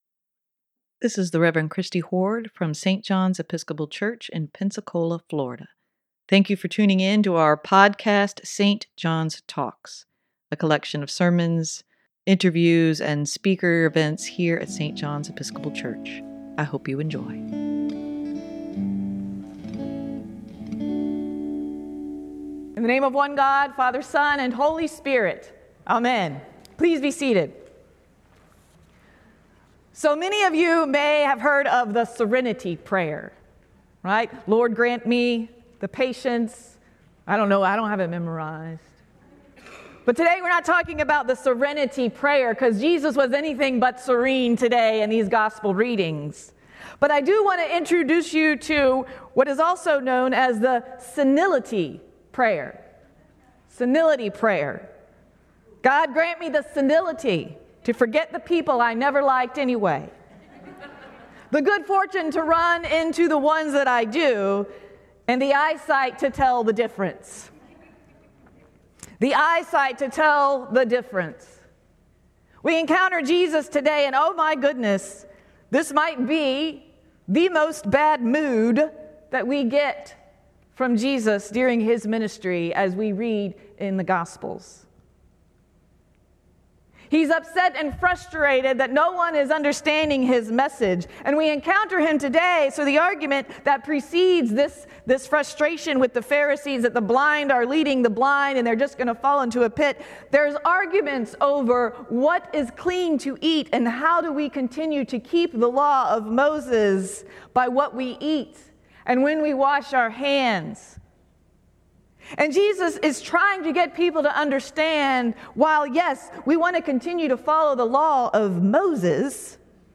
Sermon for Aug. 20, 2023: God's redemption is for everyone - St. John's Episcopal Church
sermon-8-20-23.mp3